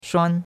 shuan1.mp3